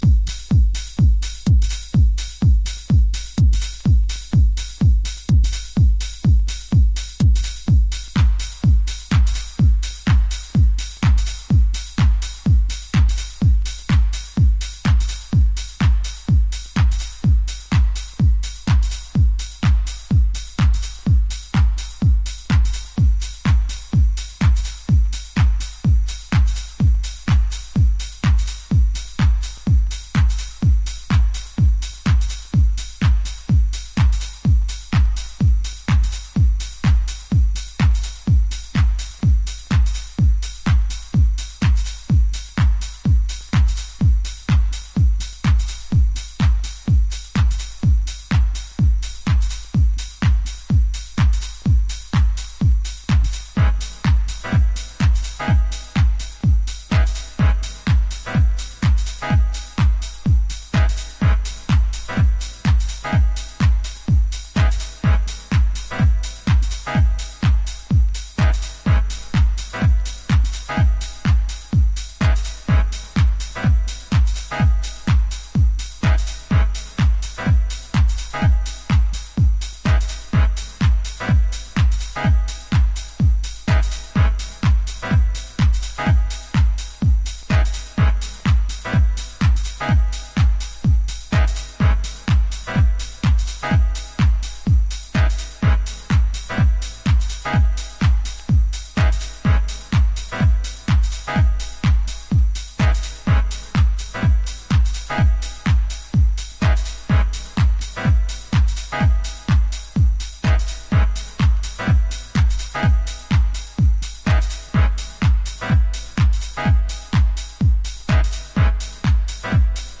House / Techno
ビートがゴキゲンなA2がオススメ